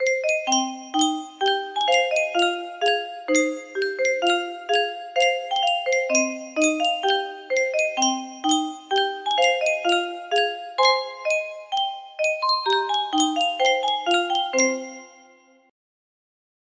Yunsheng Music Box - Lecha Dodi Y921 music box melody
Full range 60